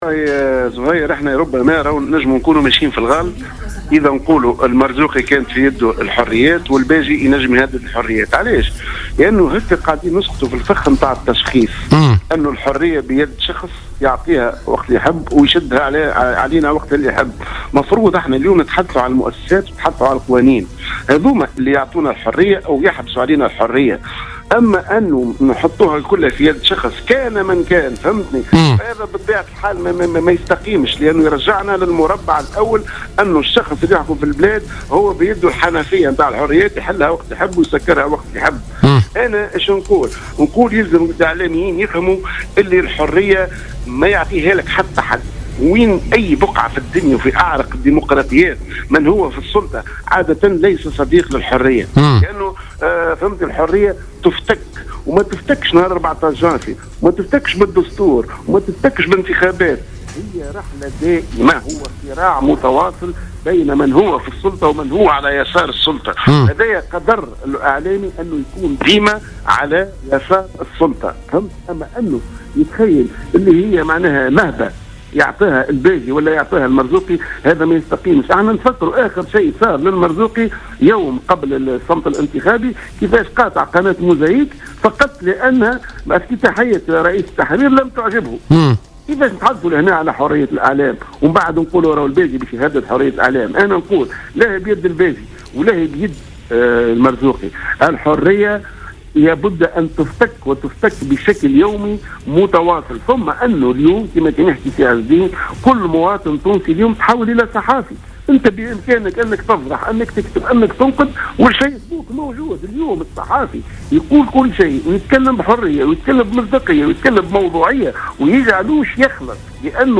قال الإعلامي لطفي العماري اليوم في مداخلة له في برنامج "بوليتيكيا" إن الإعلام سيكون بالمرصاد إلى كل محاولات السيطرة على الإعلام أو المس من حرية التعبير.